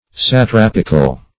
Satrapical \Sa*trap"ic*al\, a.